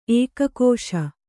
♪ ēkakōśa